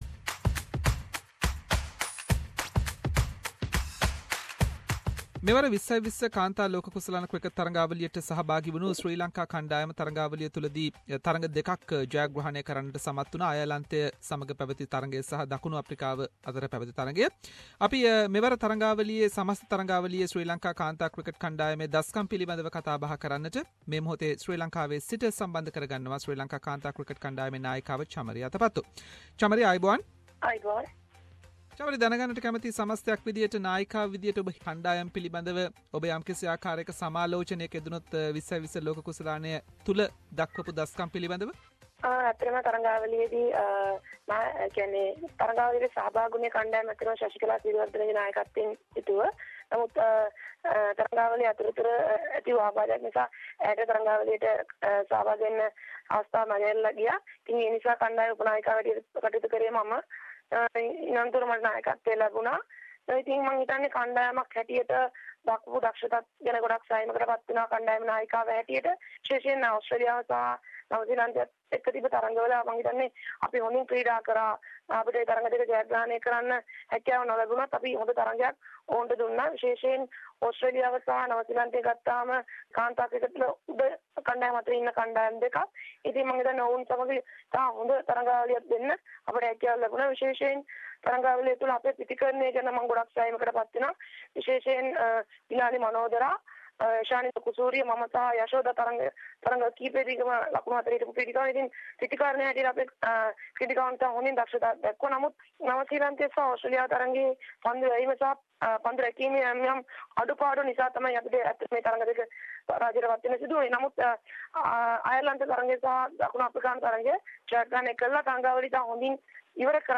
Special interview with Sri Lanka womens cricket captain Chamari Atapattu regarding their performances in 2016 World T20 tournament held in India.